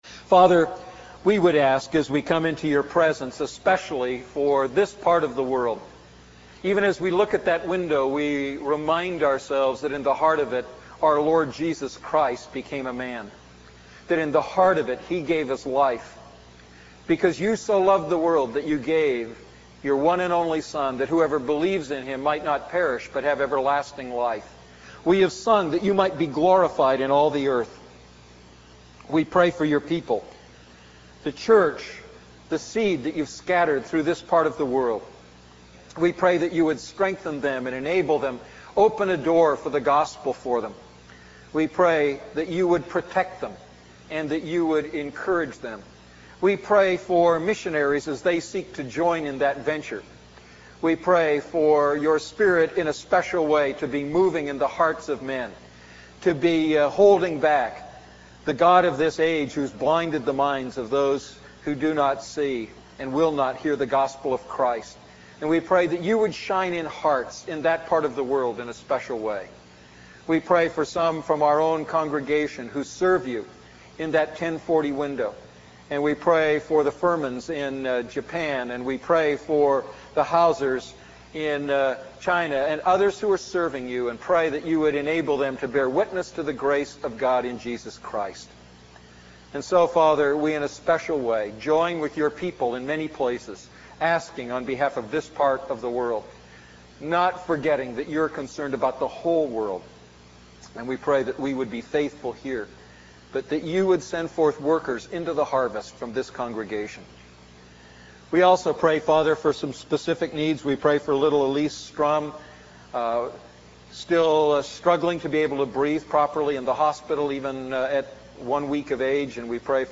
A message from the series "Luke Series II."